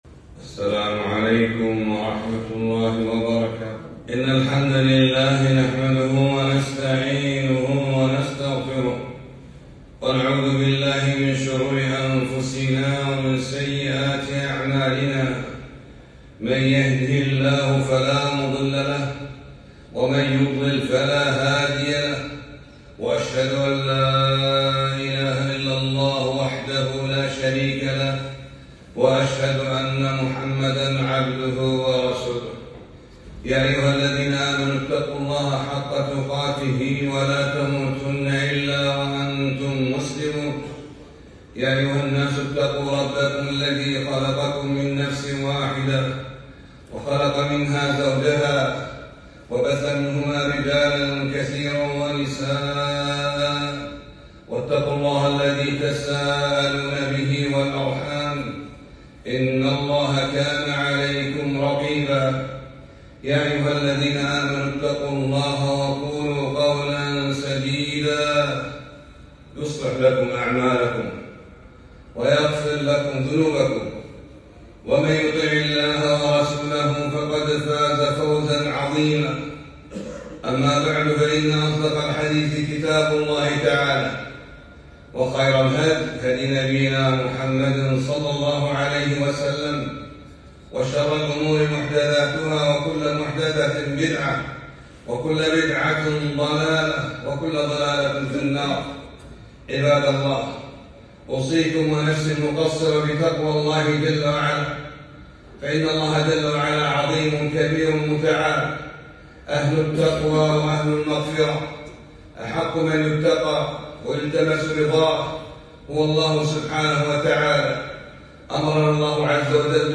خطبة - أخذ من دنياك لآخرتك